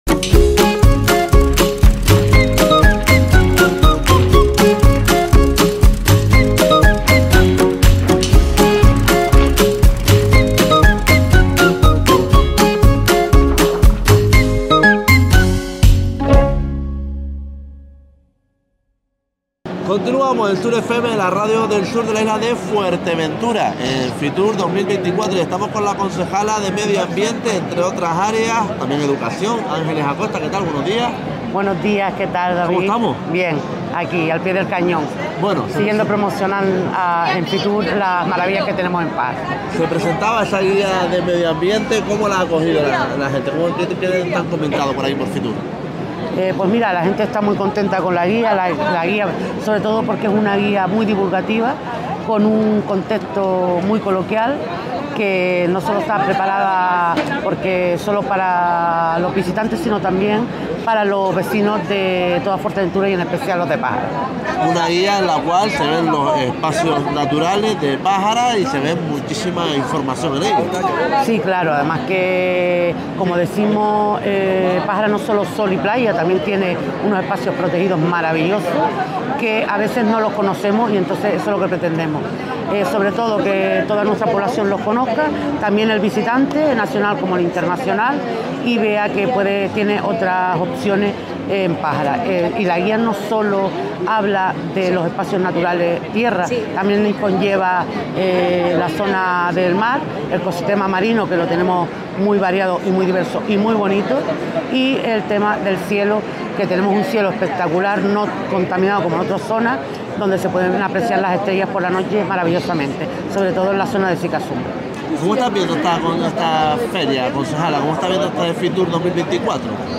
Fitur 2024: Entrevista a Ángeles Acosta
Entrevistamos a la concejala de Medio Ambiente de Pájara, Ángeles Acosta en Fitur 2024.
fitur-2024-entrevista-a-angeles-acosta.mp3